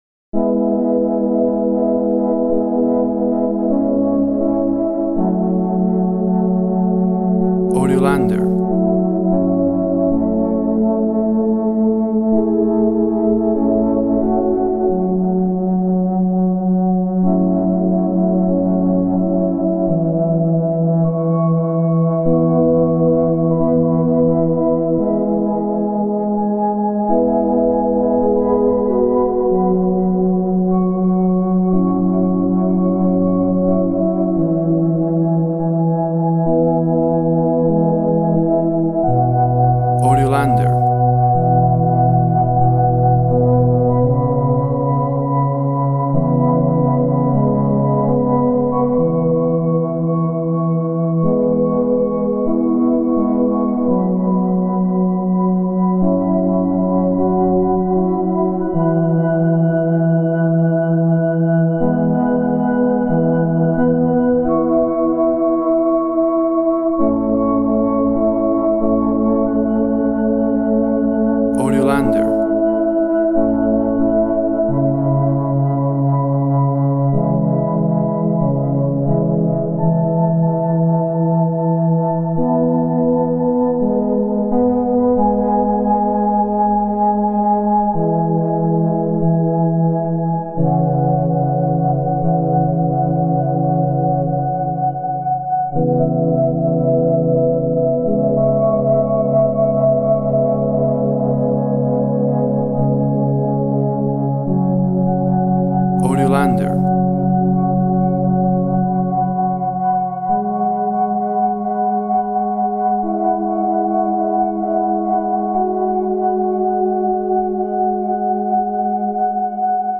Track emotional and atmospheric very introspective.
Tempo (BPM) 80